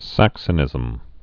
(săksə-nĭzəm)